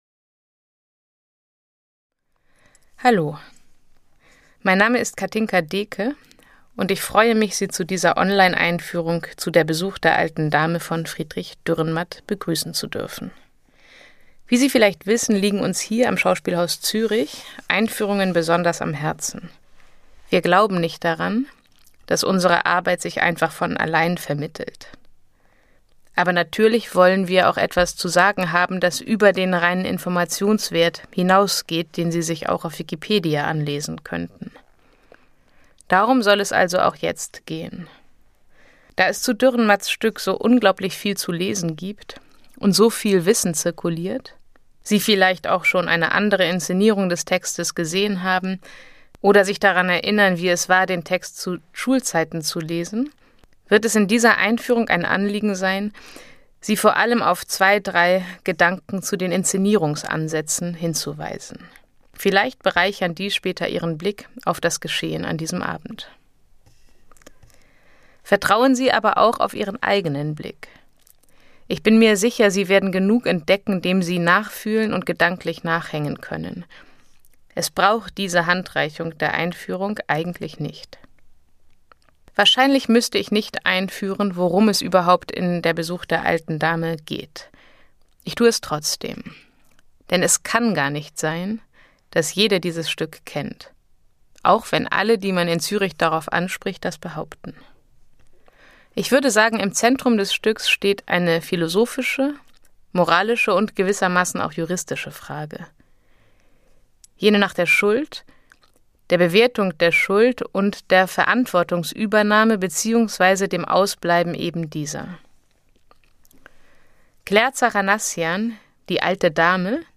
Audioeinführung zu "Der Besuch der alten Dame"
Künstler*innen und Dramaturg*innen der Produktion.